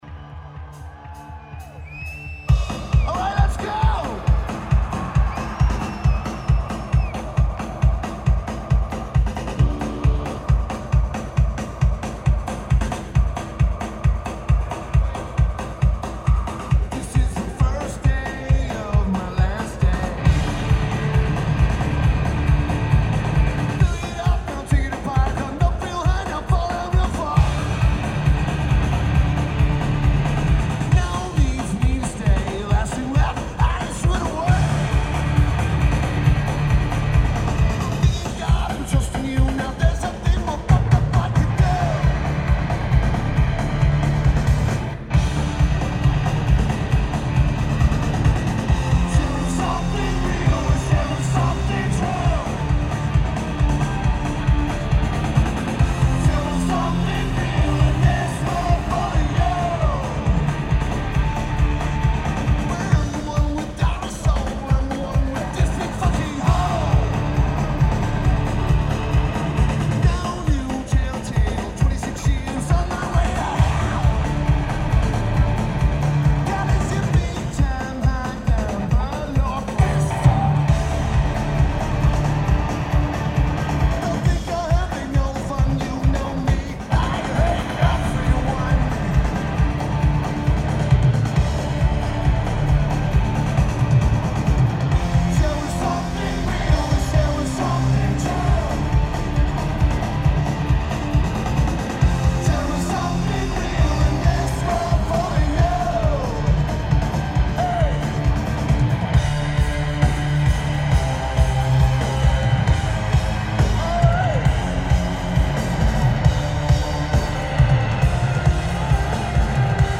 Allen City Memorial Coliseum
Lineage: Audio - AUD (Schoep MK4s + N-Box + Edirol R1)